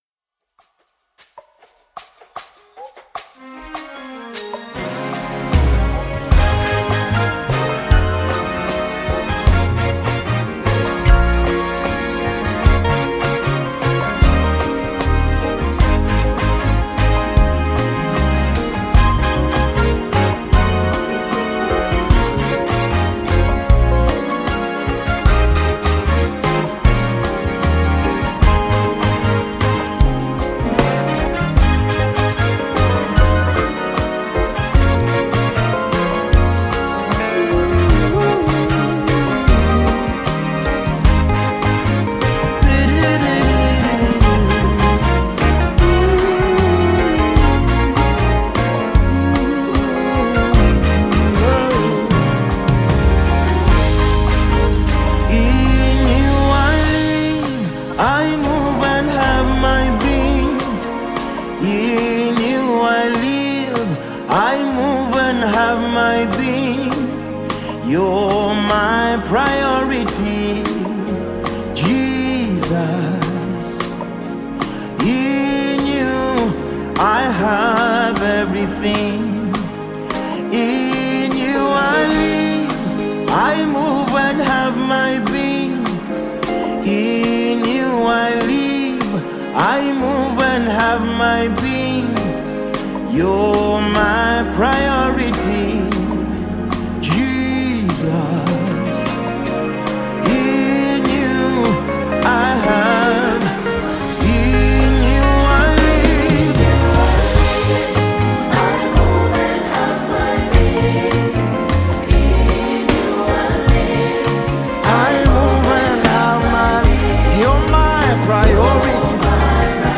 a spirit-filled gospel song